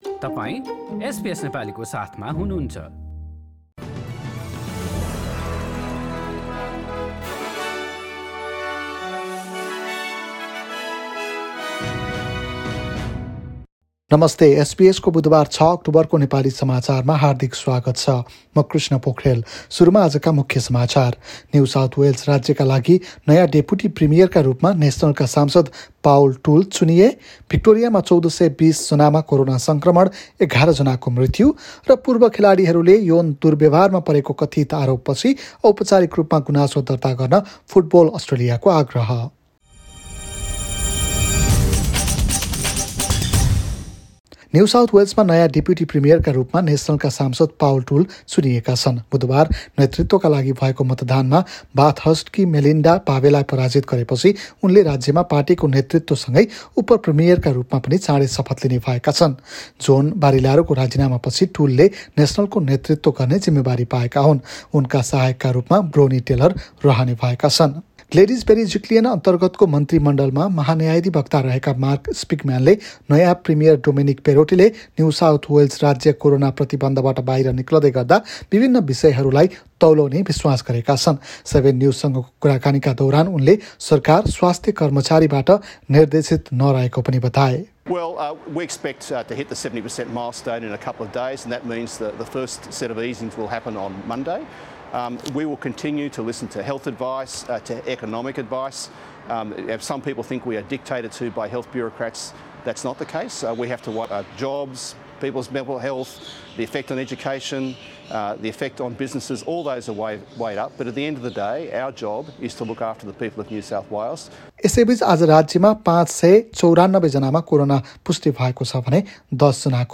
एसबीएस नेपाली अस्ट्रेलिया समाचार: बुधबार ६ अक्टोबर २०२१